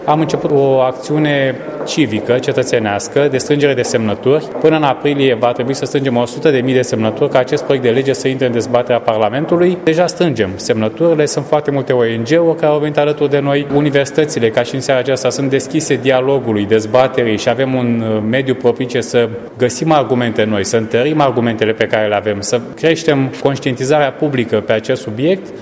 Europarlamentarul Cătălin Ivan a declarat, joi, la Tîrgu-Mureș, în cadrul unei dezbateri cu titlul „Panama Papers, Paradise Papers- Impactul asupra economiei şi reglementărilor europene”, că România trebuie să se alinieze celorlalte state pentru eliminarea acționariatului ascuns.
Evenimentul a fost găzduit de Facultatea de Stiinte Economice, Juridice si Administrative a Universității ”Petru Maior” din Tîrgu-Mureș.